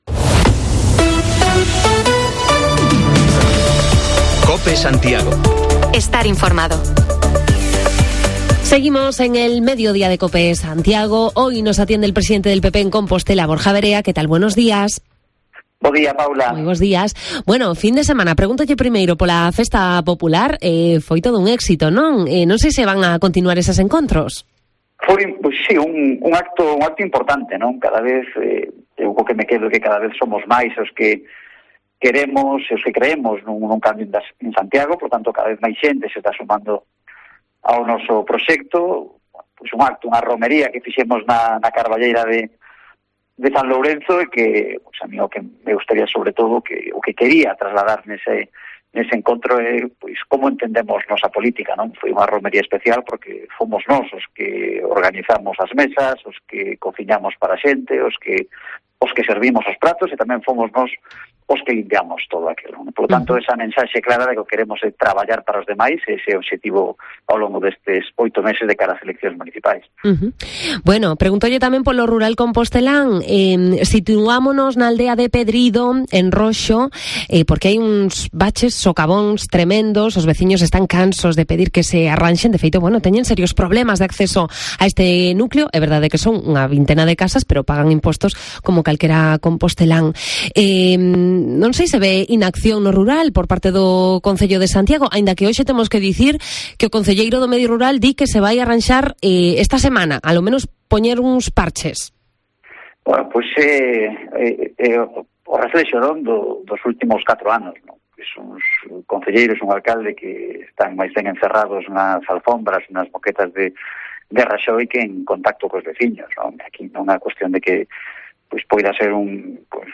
AUDIO: Nos atiende Borja Verea (pte PP Santiago) y de nuevo paseamos por Santa Minia con voces de la fiesta